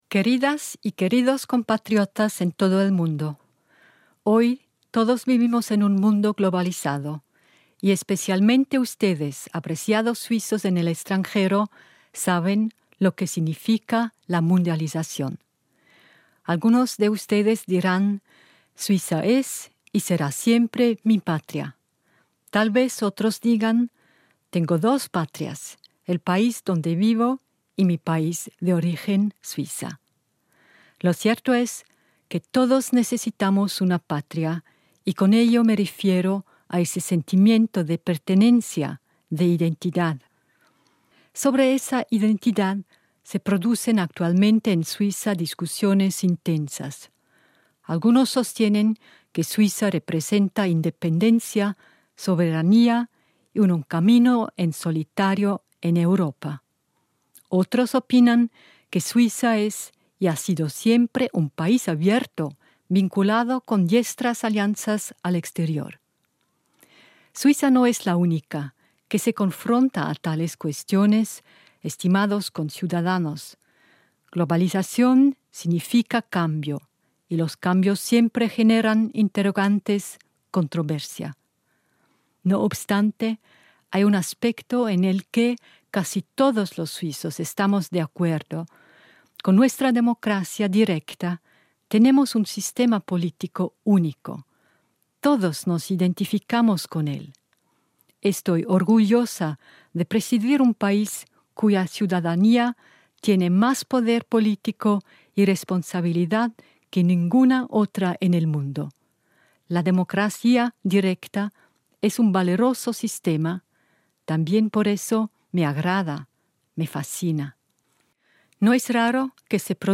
1.-August-Rede der Bundespräsidentin
La presidenta de Suiza, Simonetta Sommaruga, con ocasión de la Fiesta Nacional del 1° de agosto, dirige a los cerca de 750 000 conciudadanos que viven fuera de las fronteras helvéticas un discurso especial, en los idiomas nacionales, en inglés y en español. En su mensaje enfatiza la importancia del sistema suizo de democracia directa y el valor de cada boleta de voto.